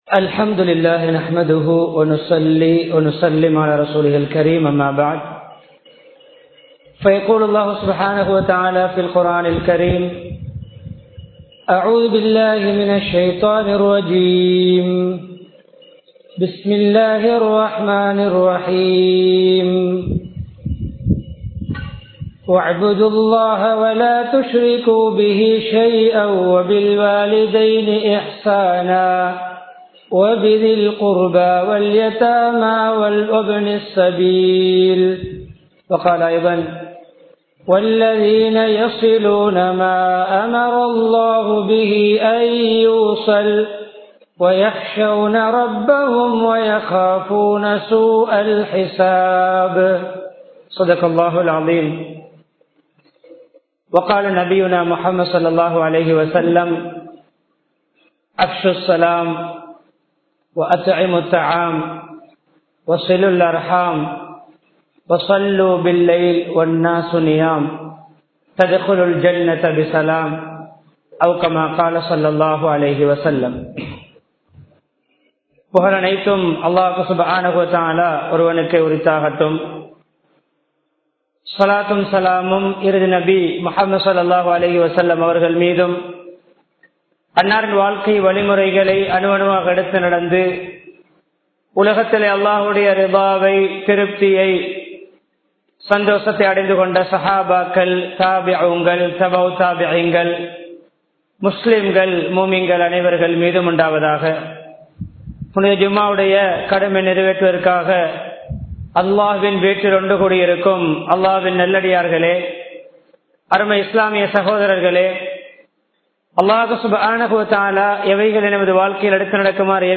பகைமையும் குரோதமும் | Audio Bayans | All Ceylon Muslim Youth Community | Addalaichenai
Colombo 02, Shahul Hameediya Street Jumua Masjith